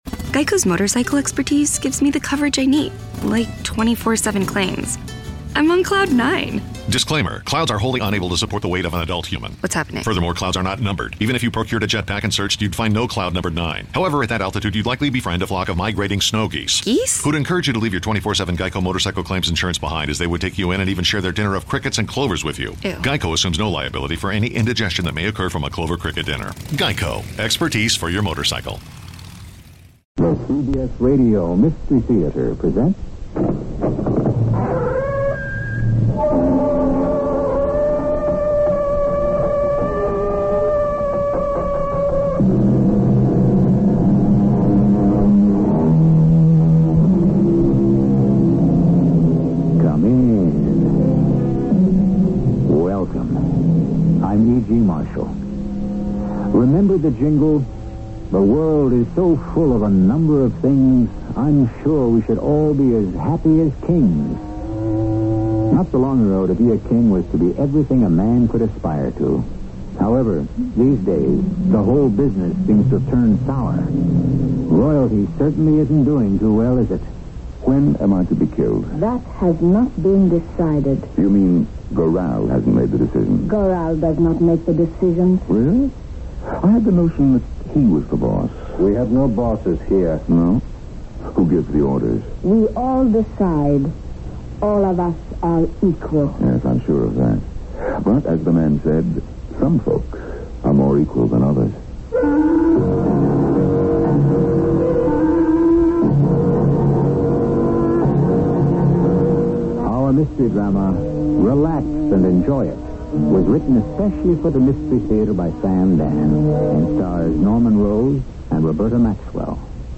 CBS Radio Mystery Theater (a.k.a. Radio Mystery Theater and Mystery Theater, sometimes abbreviated as CBSRMT) was a radio drama series created by Himan Brown that was broadcast on CBS Radio Network affiliates from 1974 to 1982